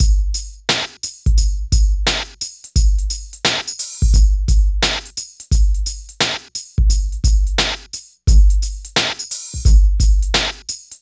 Index of /90_sSampleCDs/Best Service ProSamples vol.01 - Hip Hop and R&B Drumloops [AKAI] 1CD/Partition D/VOLUME 004